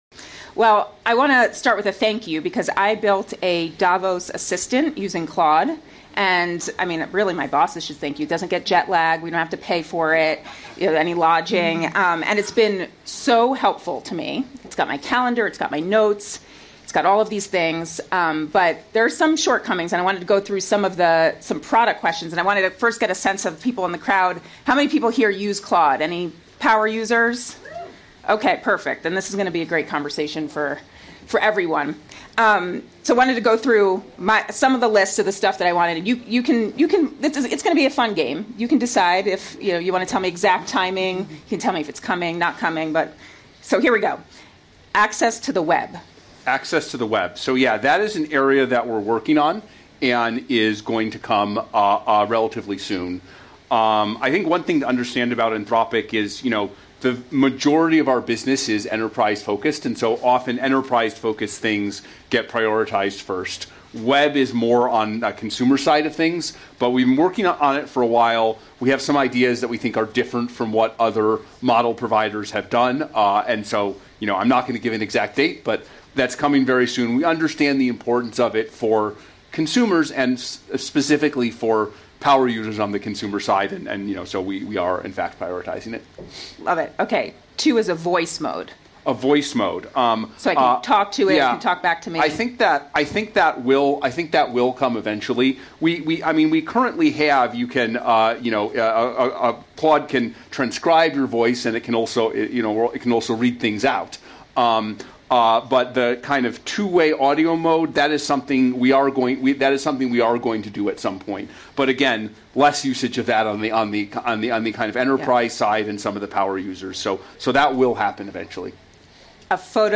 Wall Street Journal’ın Kıdemli Kişisel Teknoloji Köşe Yazarı Joanna Stern, Davos’ta Anthropic CEO’su Dario Amodei ile yaptığı son röportajı tamamladı.